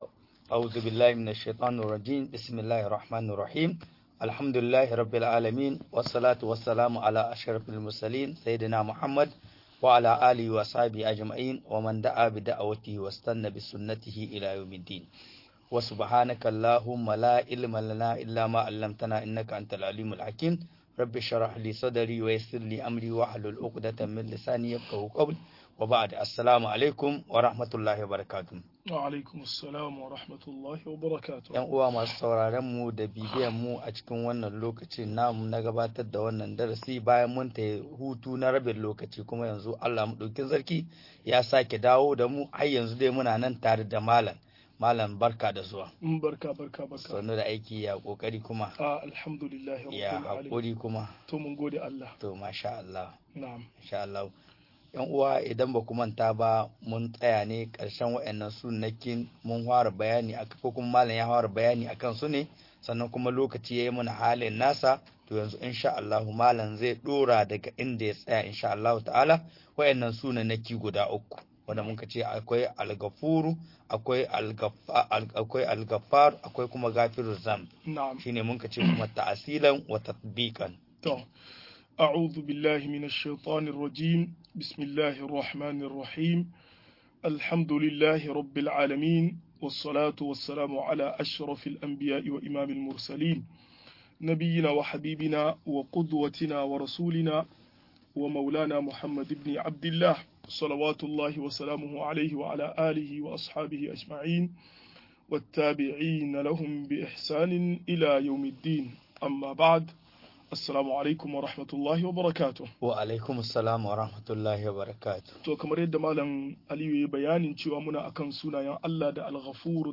Sunayen Allah da siffofin sa-06 - MUHADARA